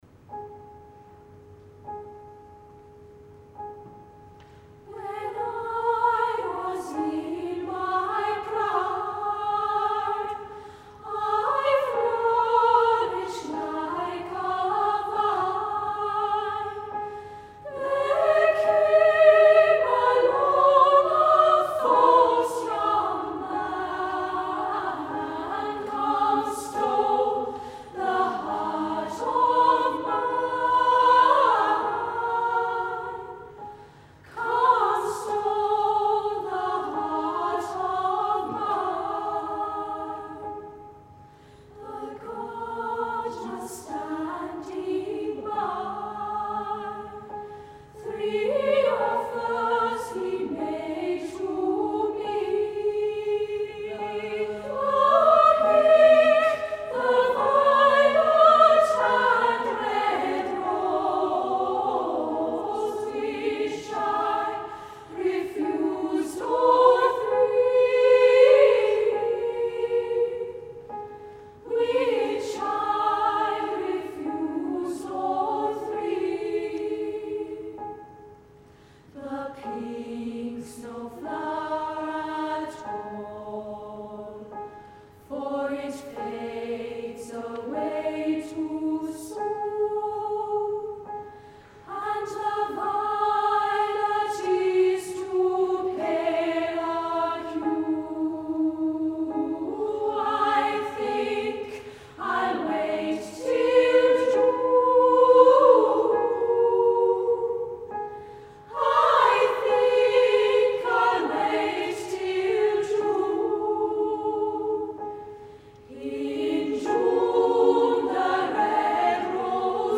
Recordings from The Big Sing National Final.
Euphony Kristin School When I Was In My Prime Loading the player ...